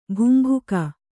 ♪ bhumbhuka